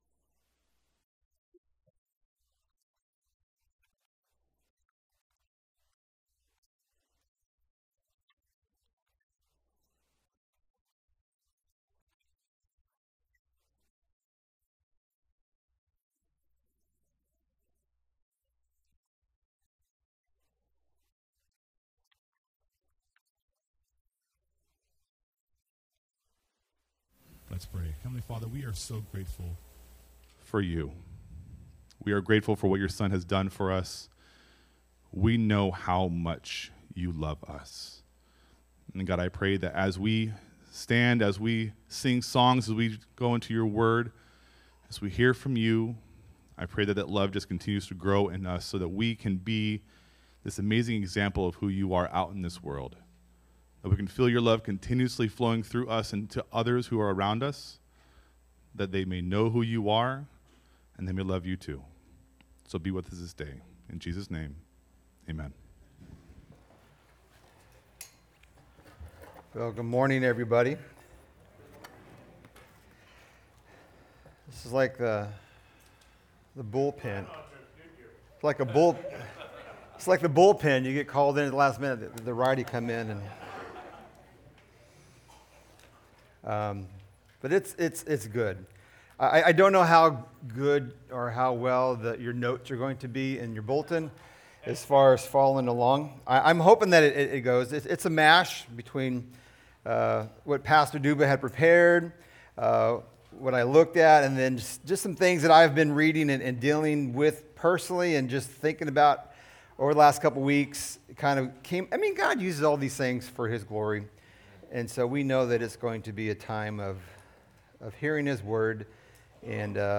Sermon Notes:Coming soon.